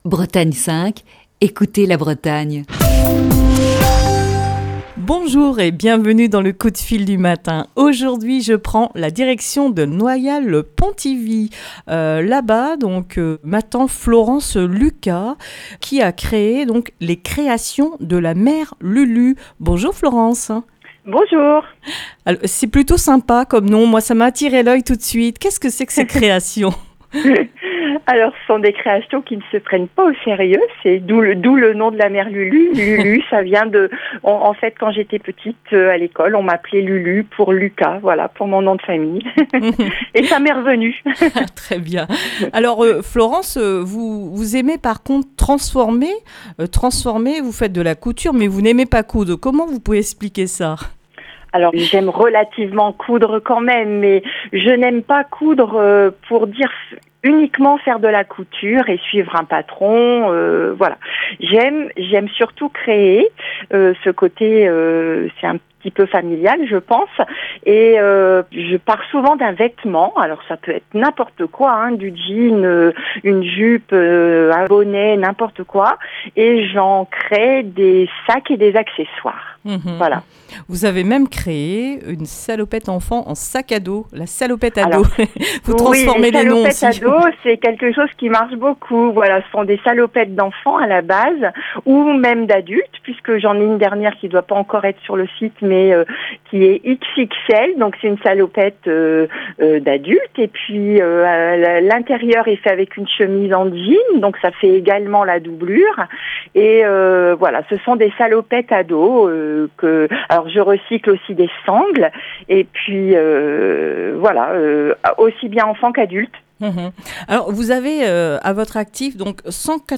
Émission du 9 juin 2020.